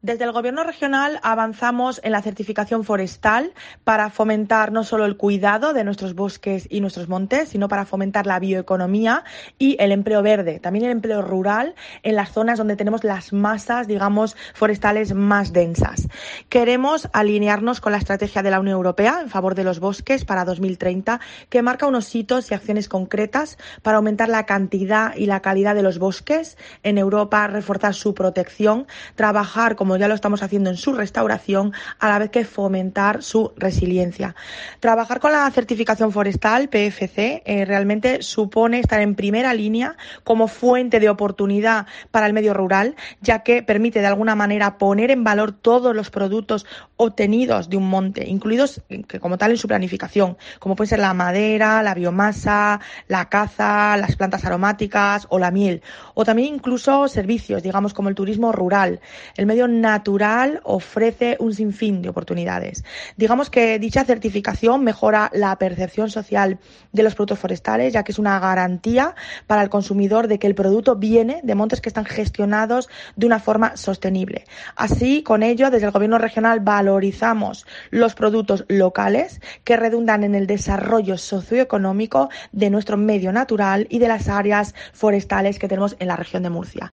María Cruz Ferreira, secretaria autonómica de Energía, Sostenibilidad y Acción Climática